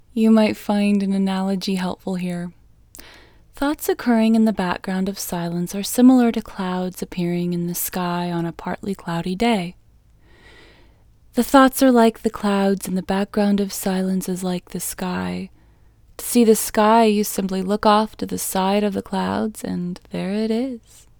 LOCATE IN English Female 15